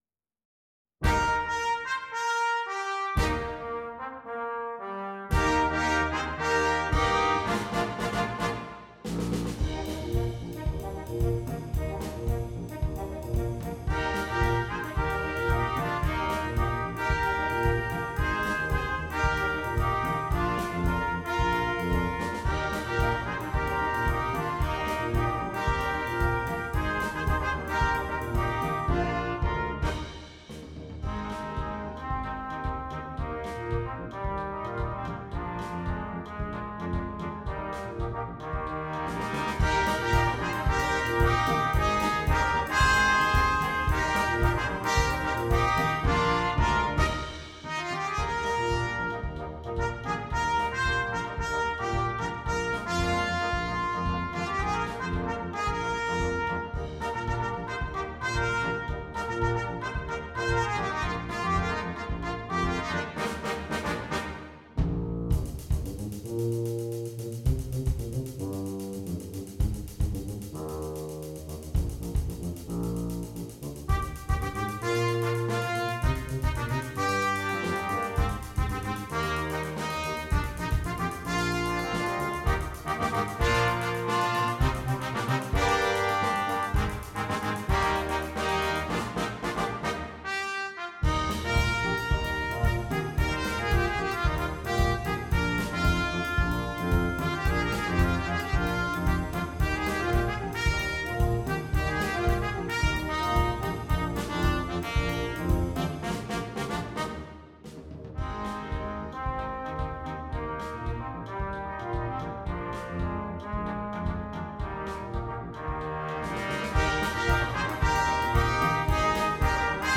Christmas
Brass Quintet (optional Drum Set)